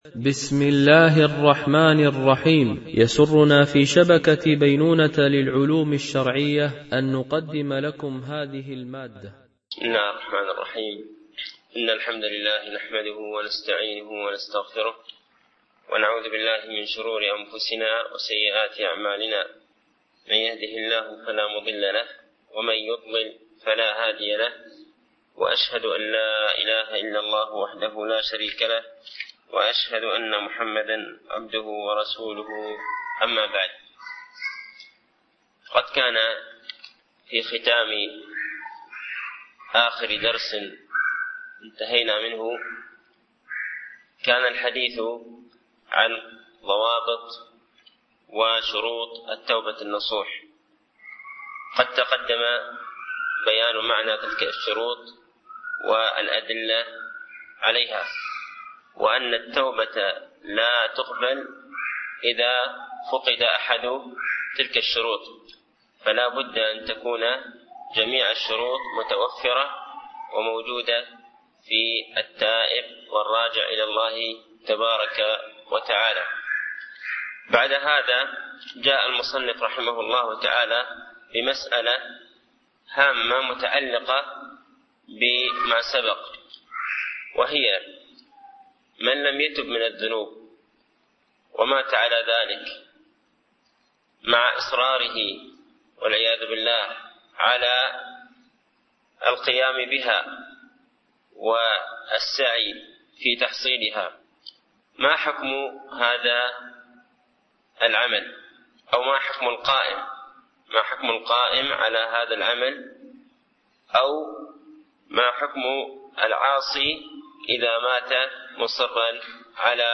شرح أعلام السنة المنشورة ـ الدرس 119 ( ما حكم من مات من الموحدين مصرا على كبيرة ؟ )
) الألبوم: شبكة بينونة للعلوم الشرعية المدة: 61:16 دقائق (14.06 م.بايت) التنسيق: MP3 Mono 22kHz 32Kbps (CBR)